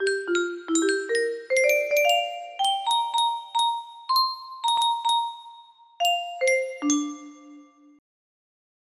Unknown Artist - Paige music box melody